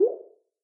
waterdrop-low.mp3